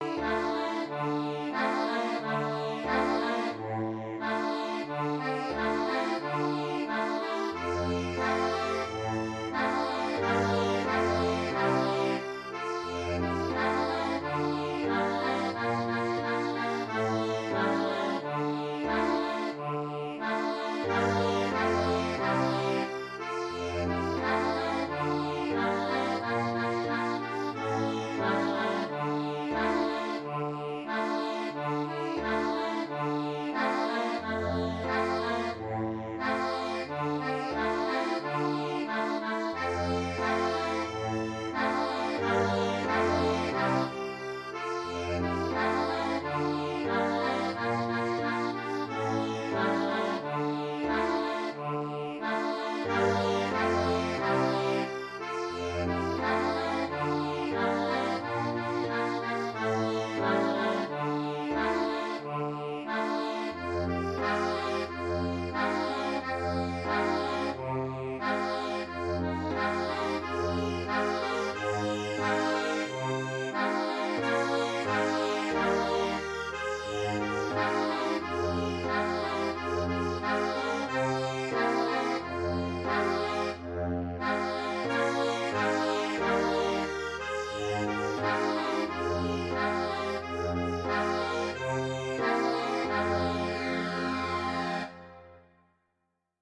Ноты для аккордеона.